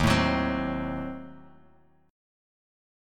D#dim7 Chord